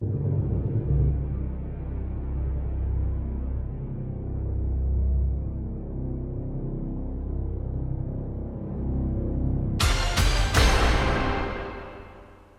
Category: Television   Right: Both Personal and Commercial
gameshow game show television full soundtrack